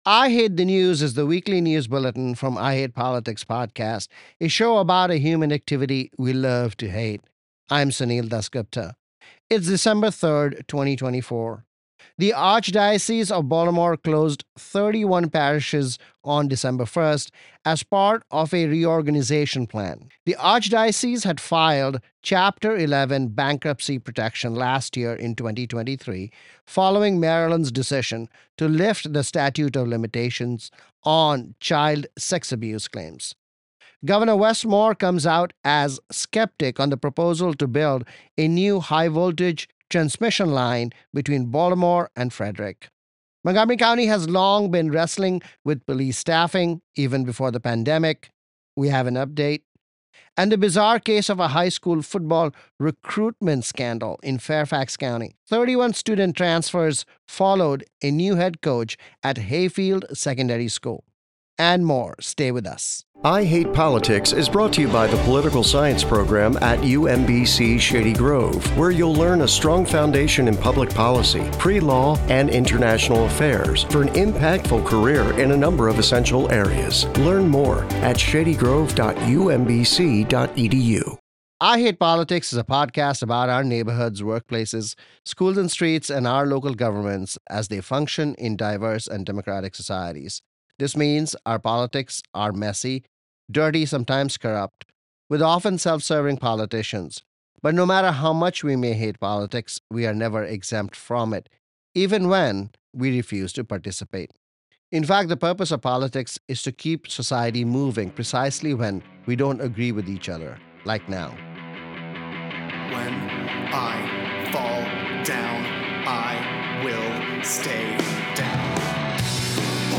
The weekly news analysis from I Hate Politics: The Archdiocese of Baltimore embarks on a major reorganization plan that will close 31 parishes. MD Gov Moore joins county leaders to question a power transmission project that will feed data centers in Virginia. Police staffing woes continue for Montgomery County, MD, among other jurisdictions.